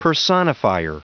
Prononciation du mot personifier en anglais (fichier audio)
Vous êtes ici : Cours d'anglais > Outils | Audio/Vidéo > Lire un mot à haute voix > Lire le mot personifier